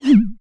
barkeep_dryfire_01.wav